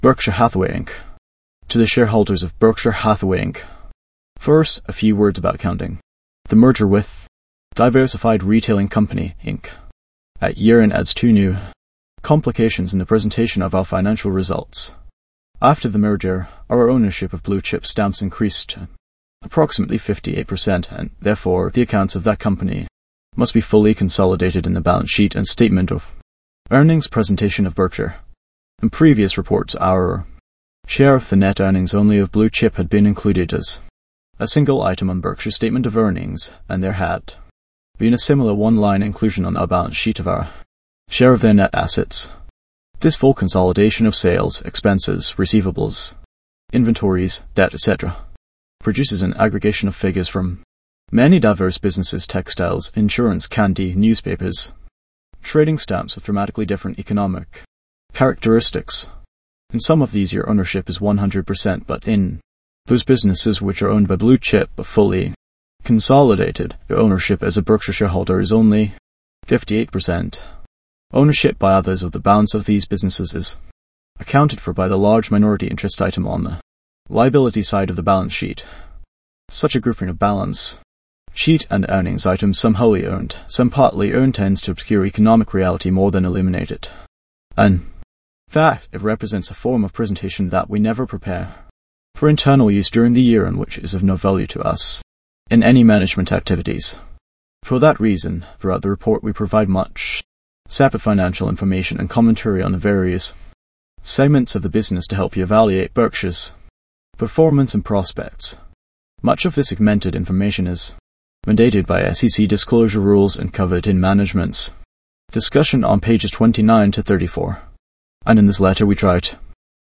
value-investors-tts
a voice model for